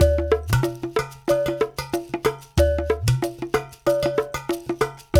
93 -UDU 01R.wav